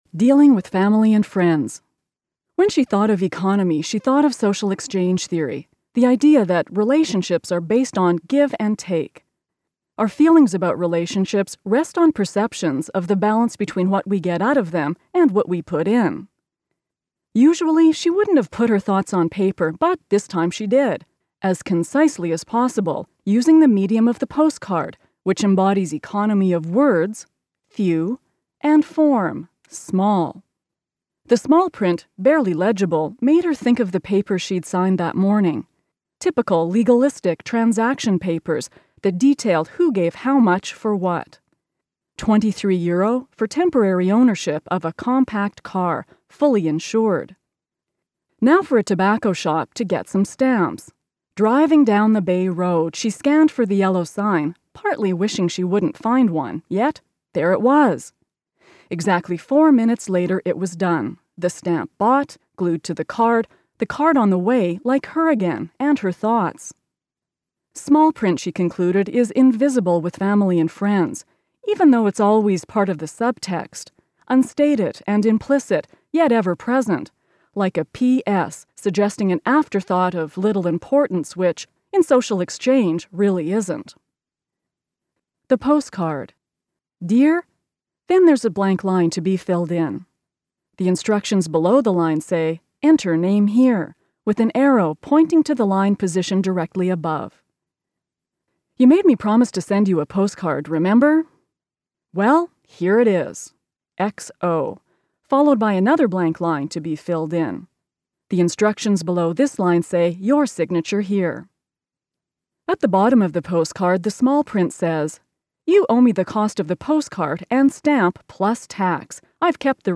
There’s something about the clarity and enunciation that is SO US!